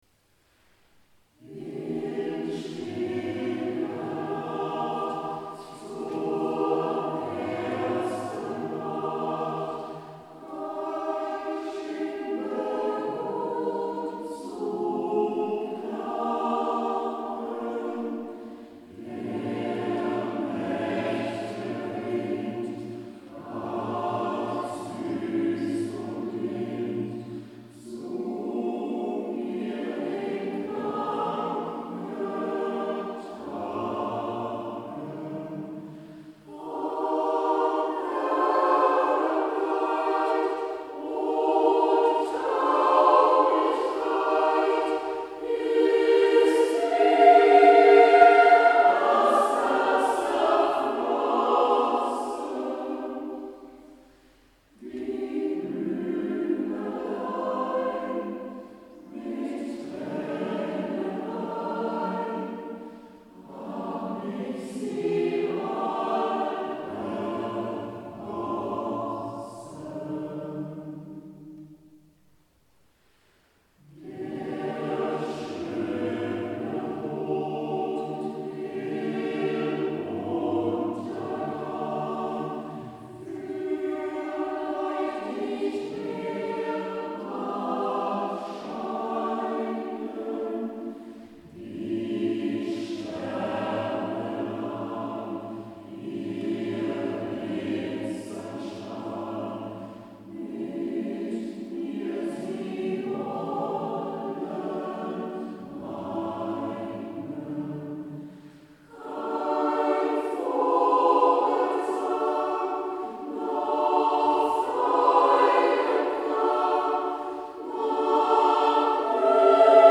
Hörbeispiele der Kantorei
Der Mond ist aufgegangen (Chor-Improvisation)